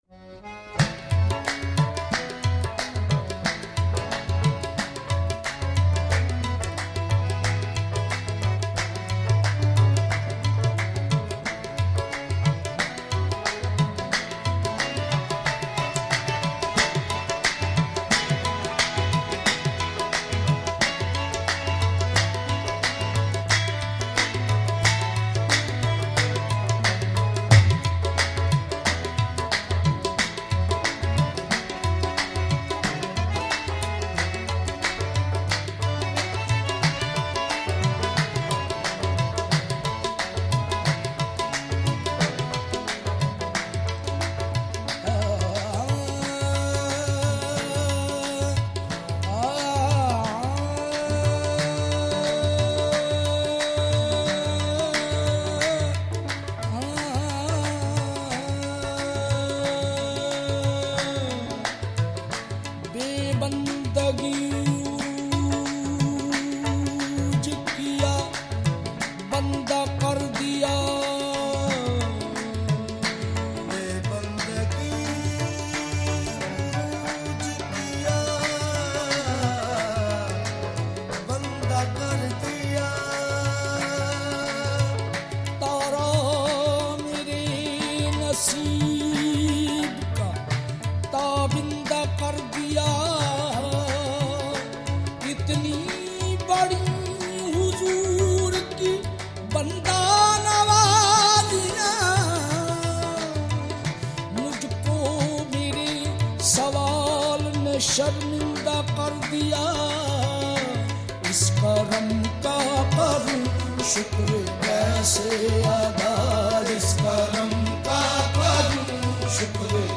MP3 Qawwali